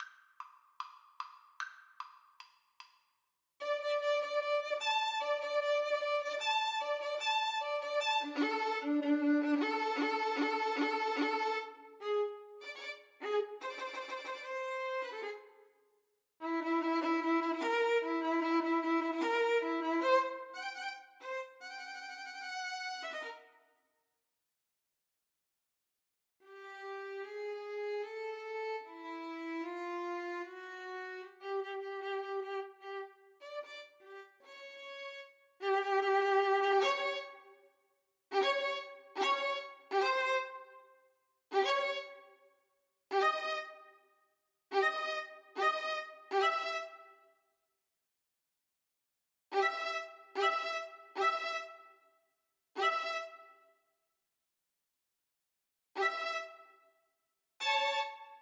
Free Sheet music for Violin Duet
4/4 (View more 4/4 Music)
= 150 Allegro Moderato (View more music marked Allegro)
D major (Sounding Pitch) (View more D major Music for Violin Duet )
Classical (View more Classical Violin Duet Music)